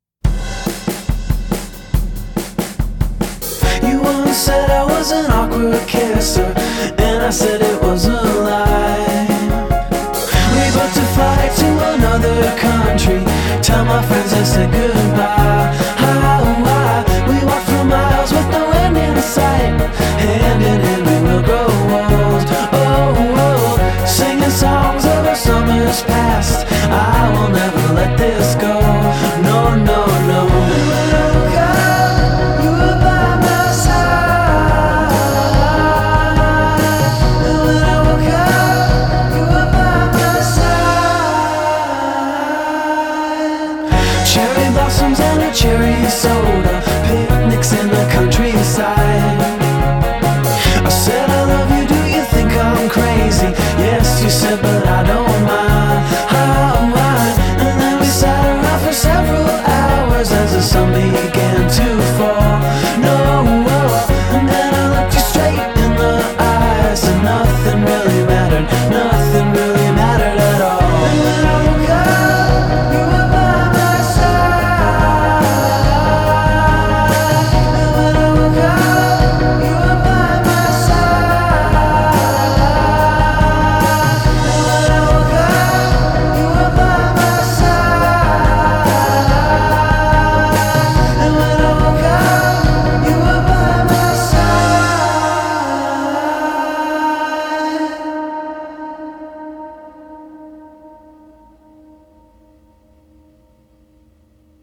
Like a poppier, riffier